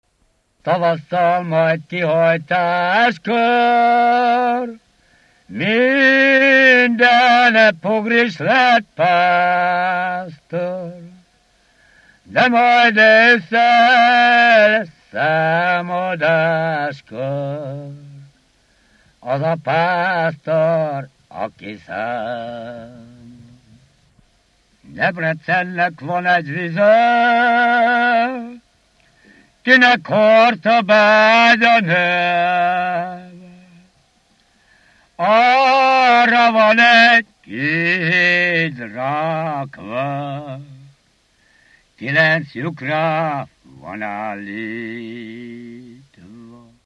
Alföld - Bihar vm. - Sáránd
ének
Gyűjtő: Sárosi Bálint
Stílus: 1.2. Ereszkedő pásztordalok
Szótagszám: 8.8.8.8
Kadencia: 8 (5) b3 1